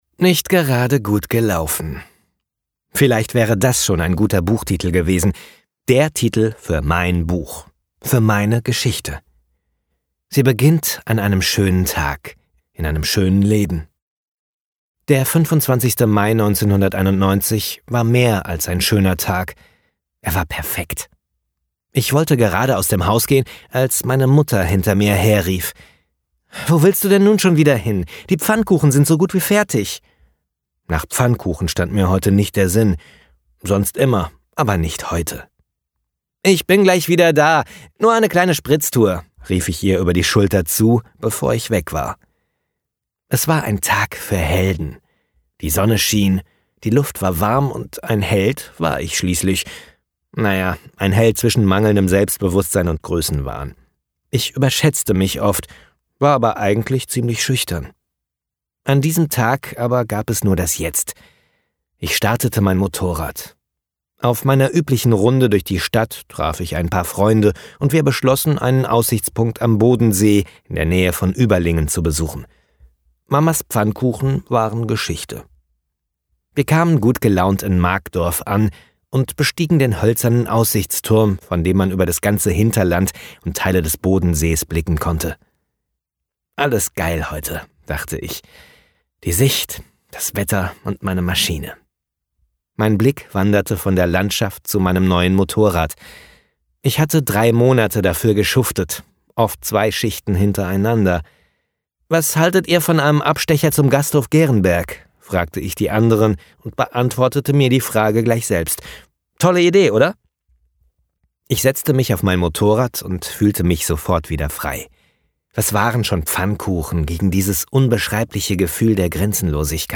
Das mundgemalte Hörbuch
roll_on_hoerprobe_1.mp3